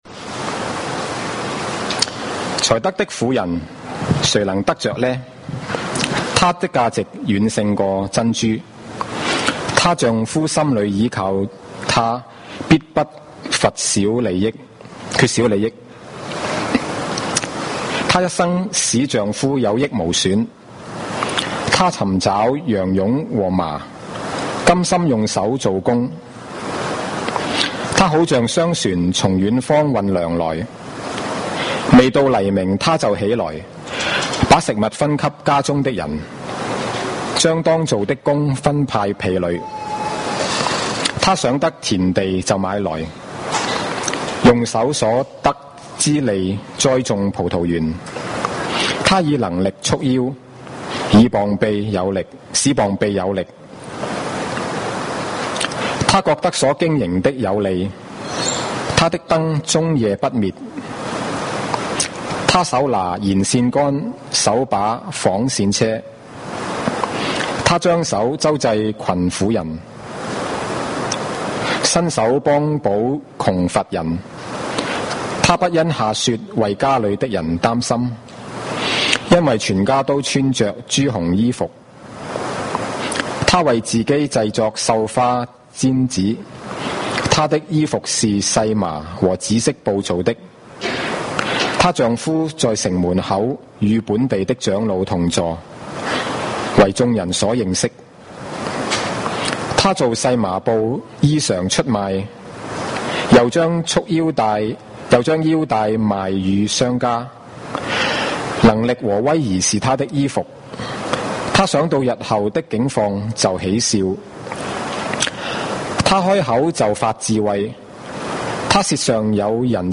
華埠粵語三堂